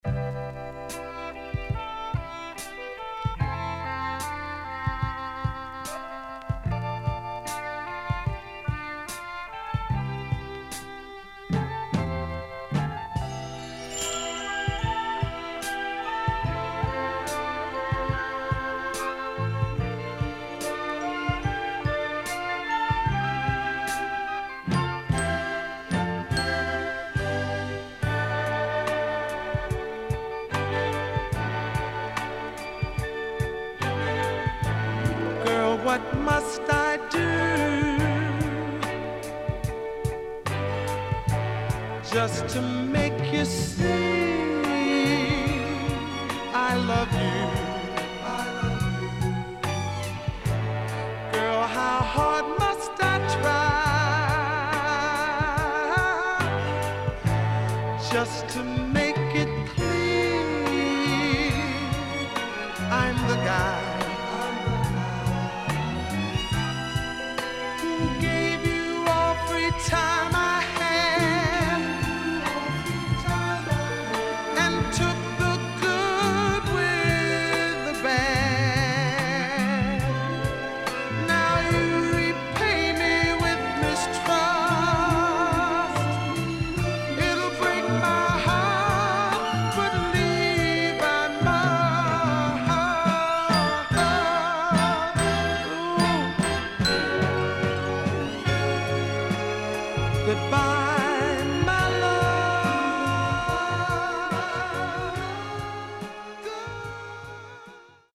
HOME > Back Order [SOUL / OTHERS]